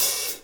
hat 2.wav